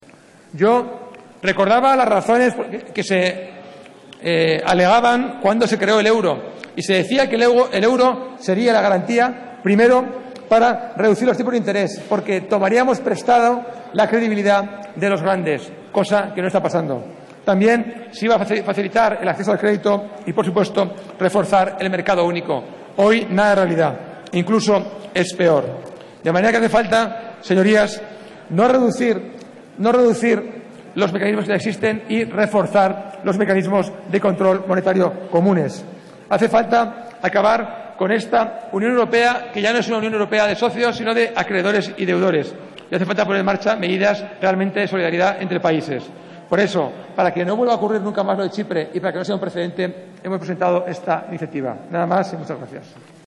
Juan Moscoso del Prado. Interviene en el pleno del Congreso para exigir al gobierno que no apoye soluciones como la aplicada en Chipre 16/04/2013